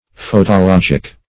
Search Result for " photologic" : The Collaborative International Dictionary of English v.0.48: Photologic \Pho`to*log"ic\, Photological \Pho`to*log"ic*al\, a. Pertaining to photology, or the doctrine of light.
photologic.mp3